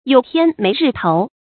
有天没日头 yǒu tiān méi rì tóu
有天没日头发音